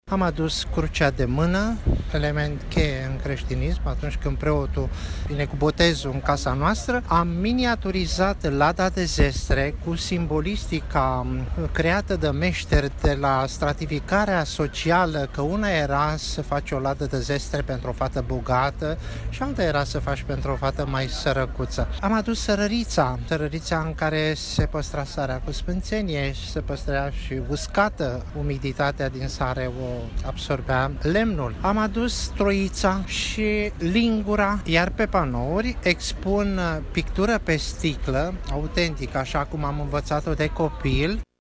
Târgul este una din atracțiile acestei ediții a Zilelor Târgumureșene care se desfășoară până mâine în Piața Trandafirilor din municipiu.